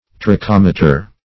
Search Result for " trochometer" : The Collaborative International Dictionary of English v.0.48: Trochometer \Tro*chom"e*ter\, n. [Gr.